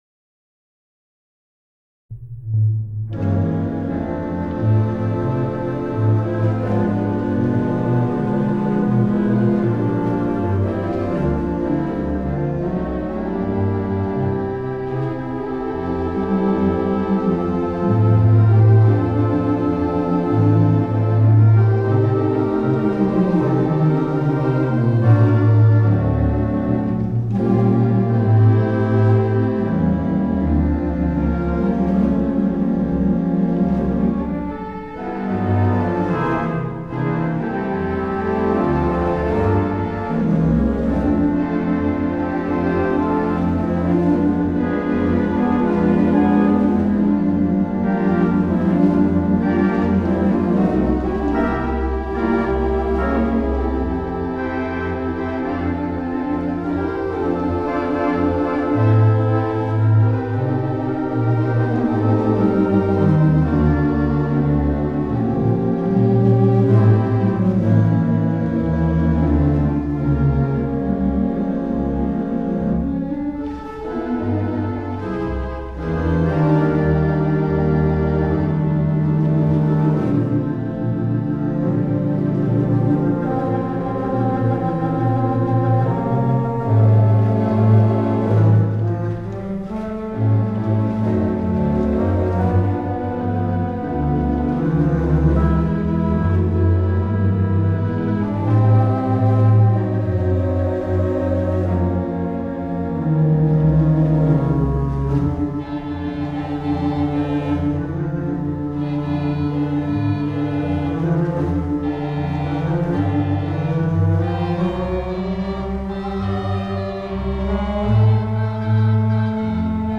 The Mighty WurliTzer on the stage
Big Band Sounds